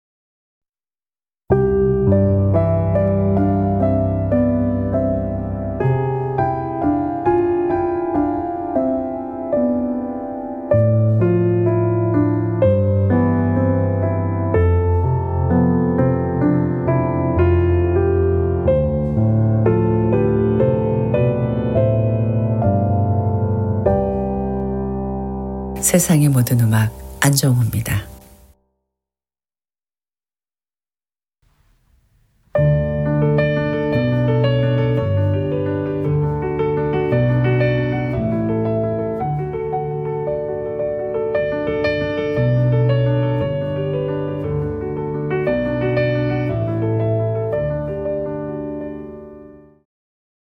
2024년 1월 14일부터 사용된 주말 로고송.
피아노